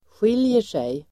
Uttal: [sj'il:jer_sej]